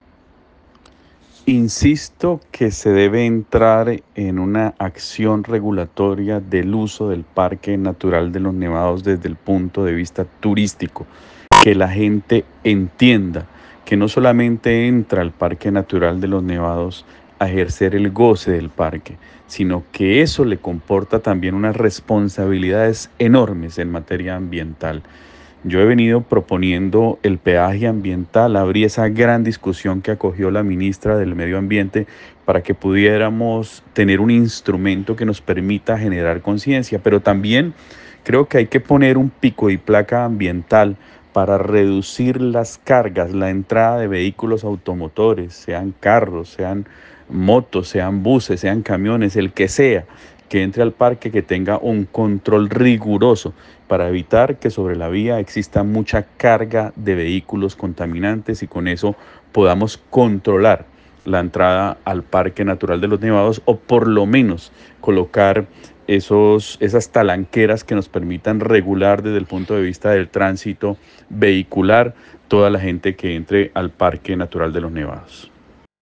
Fue en la celebración del Día del Turismo, que se desarrolló en el parque Murillo Toro de Ibagué, en el que Alexander Castro, secretario de Cultura y Turismo del Tolima, propuso que como mecanismo de protección y cuidado del Parque Natural Nacional Los Nevados, se implementen acciones como el peaje ambiental y el pico y placa ambiental.